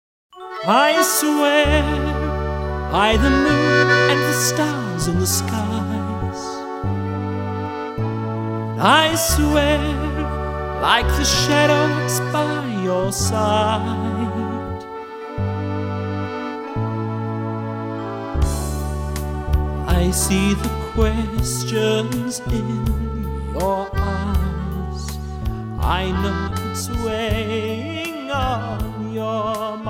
MIDI accordion with vocals